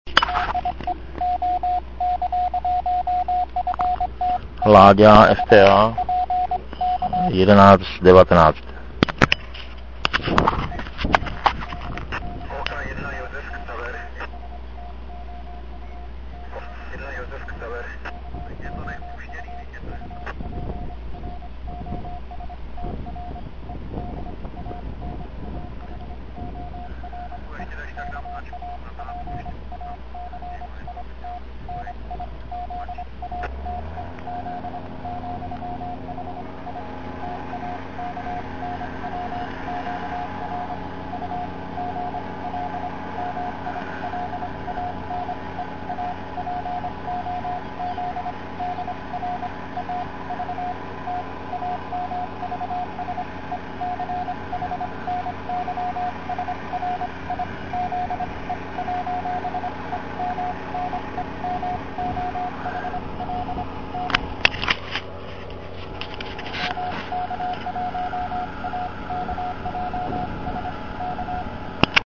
Nahrávky nejsou nejkvalitnější.
Nahrávku jsem musel dělat tak, že jsem "špuntové" sluchátko přikládal na mp3 nahravač.